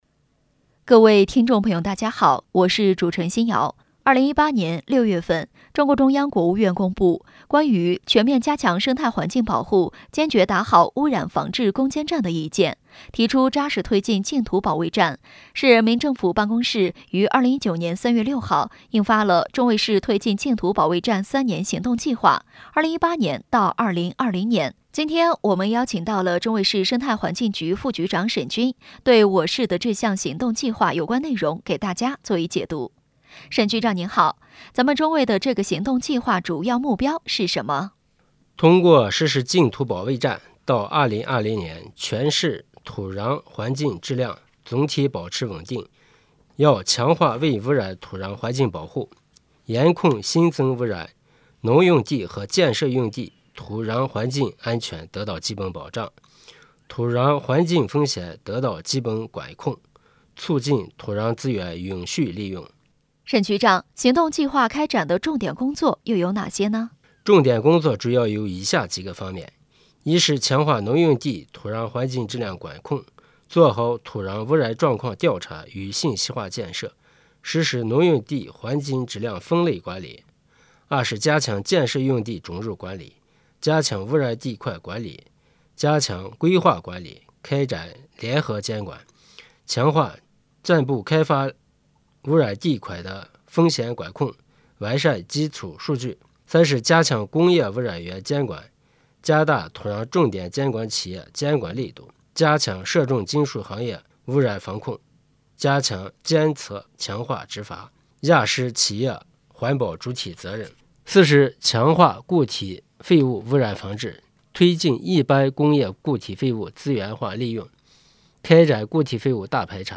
当前位置：首页 > 政民互动 > 在线访谈